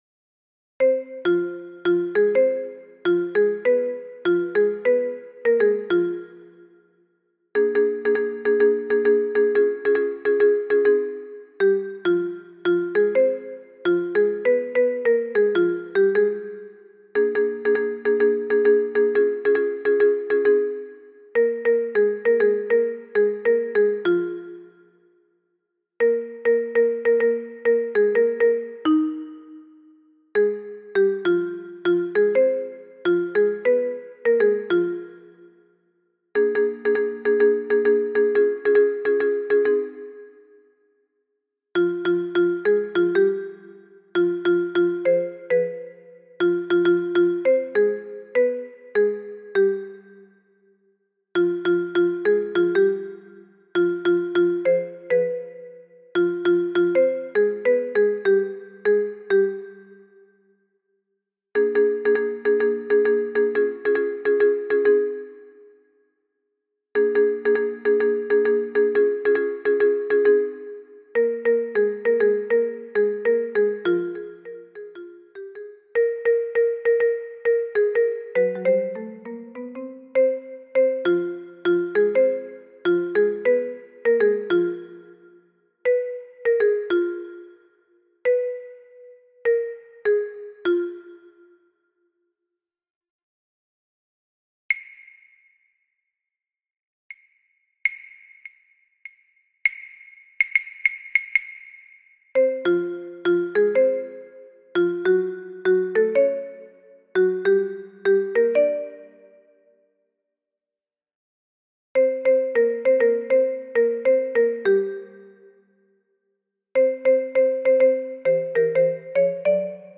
Soprano et autres voix en arrière-plan